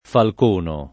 falcono [ falk 1 no ]